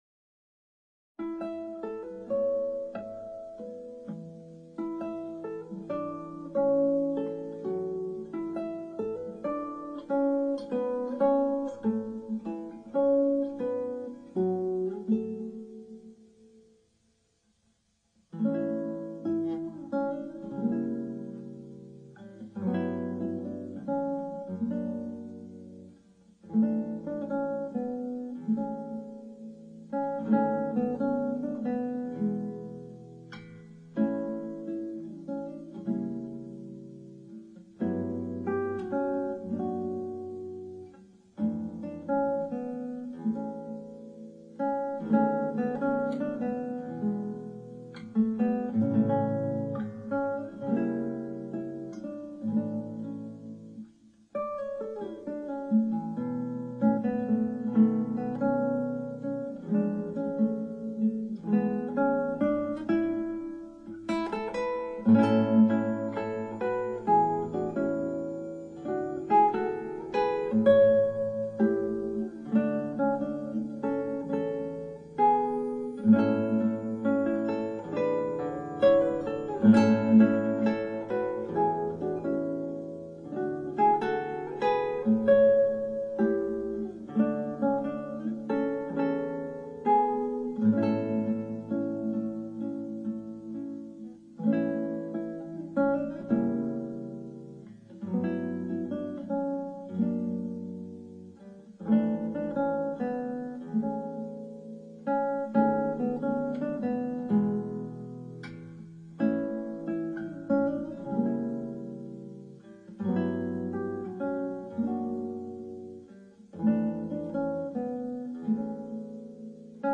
クラシックギター　ストリーミング　コンサートサイト